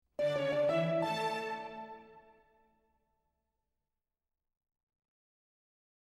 a sonic signature